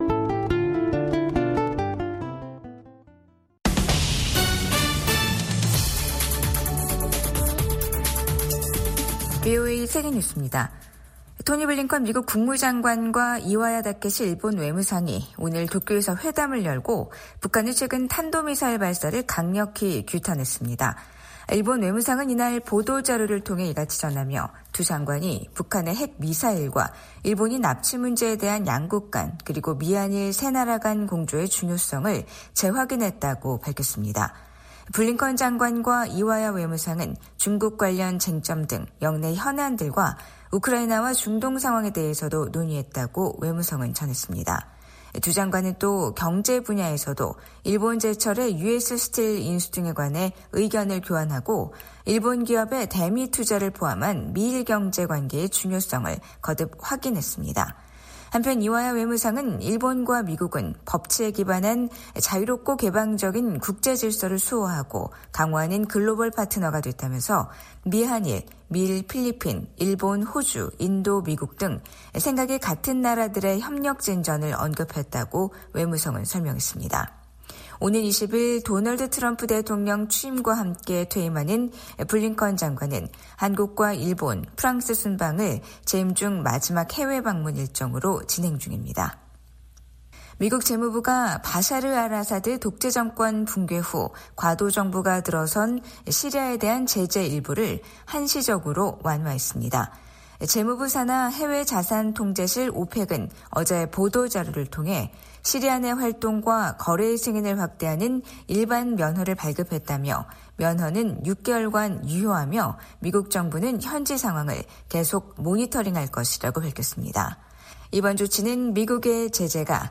생방송 여기는 워싱턴입니다 2025/1/7 저녁